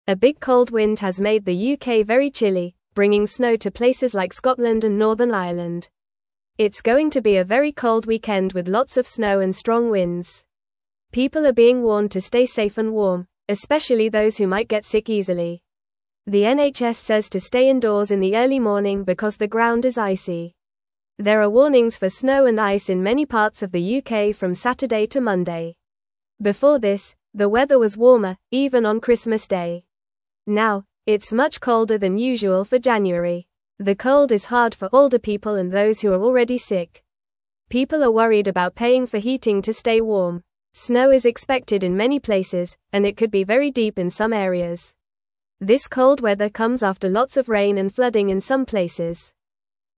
Fast
English-Newsroom-Beginner-FAST-Reading-Big-Cold-Wind-Brings-Snow-to-the-UK.mp3